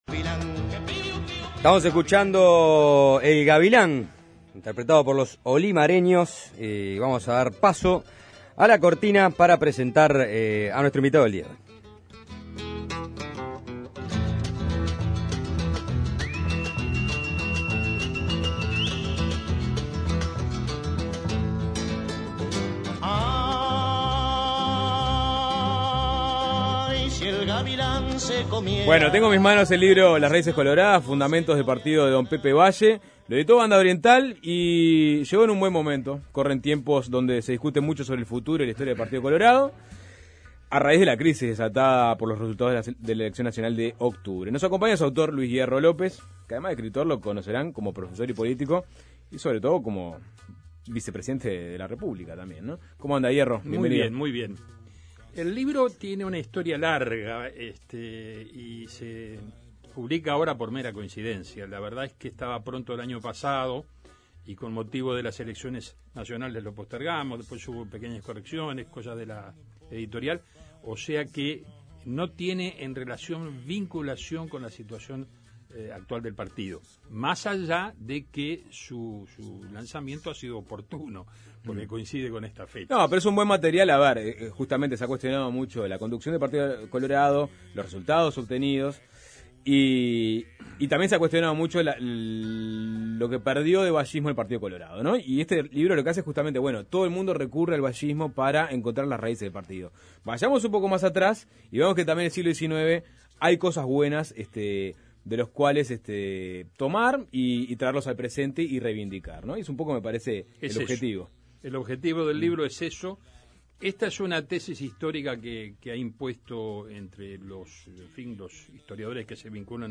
Hay controles a nivel de la opinión pública"; afirmó Hierro entrevistado en Suena Tremendo.